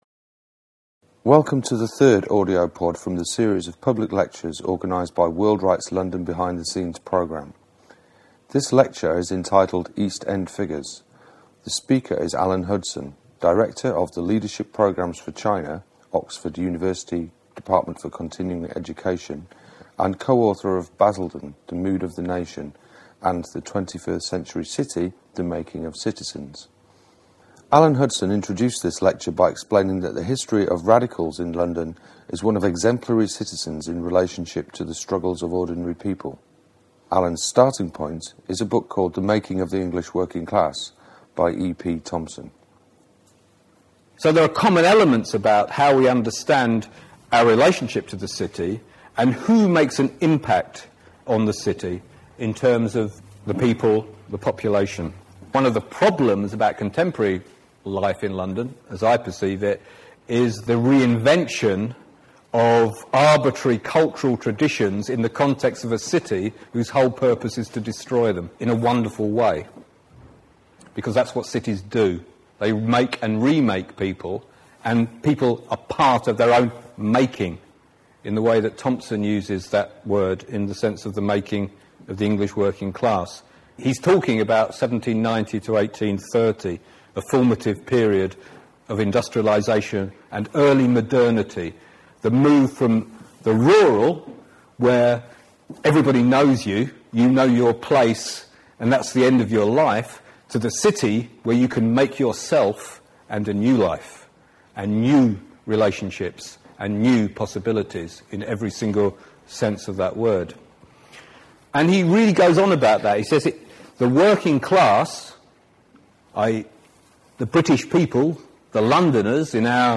Public Lectures: East End Figures